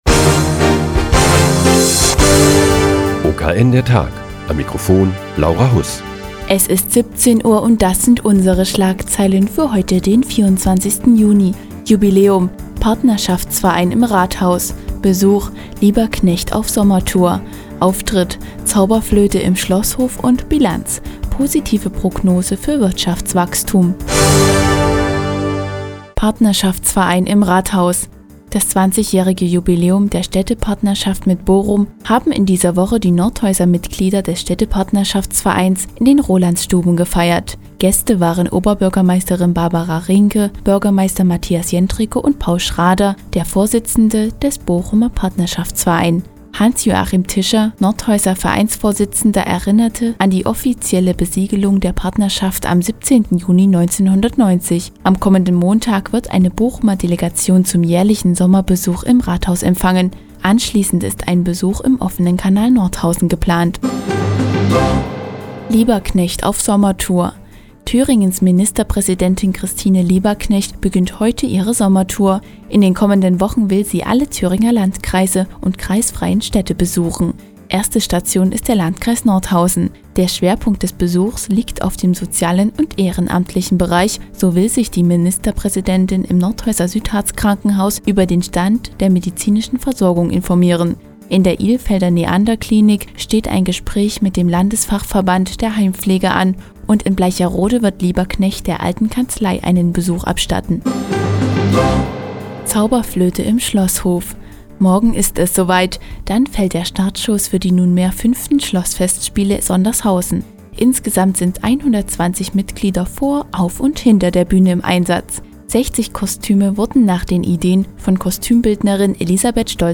Die tägliche Nachrichtensendung des OKN ist nun auch in der nnz zu hören. Heute geht es um das 20-jährige Jubiläum der Nordhäuser Städtepartnerschaft mit Bochum und die morgige Premiere der Schlossfestspiele in Sondershausen.